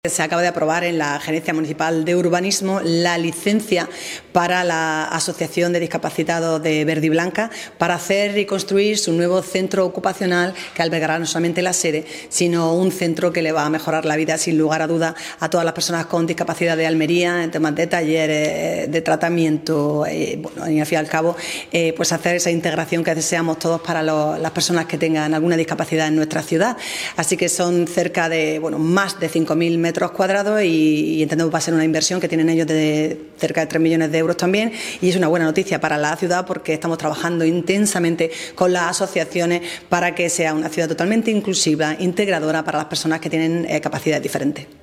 La alcaldesa de Almería, María del Mar Vázquez, reconoce en esta iniciativa, “un proyecto  inclusivo y sostenible que marcará un antes y un después en la atención a personas con discapacidad”
CORTE-ALCALDESA-VERDIBLANCA.mp3